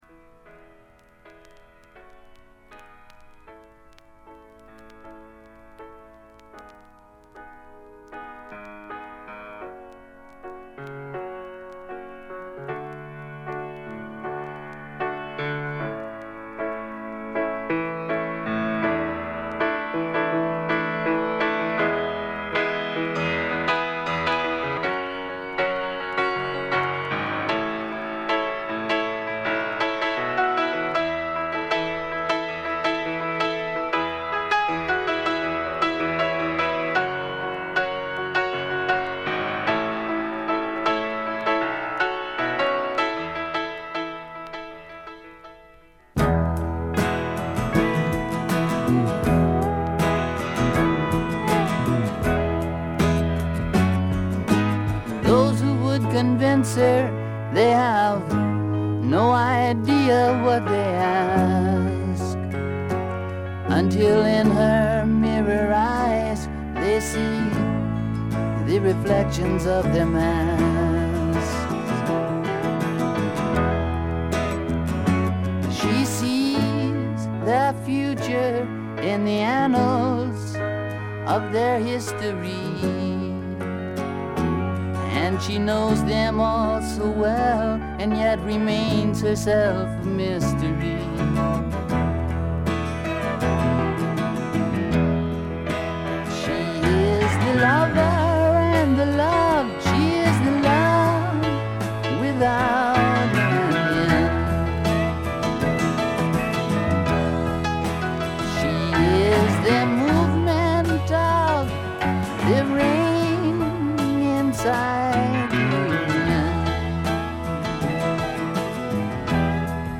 ホーム > レコード：米国 SSW / フォーク
チリプチ、散発的なプツ音が多め大きめ。
全編通してびしっと決まったシンガー・ソングライター／スワンプロックの理想郷。
試聴曲は現品からの取り込み音源です。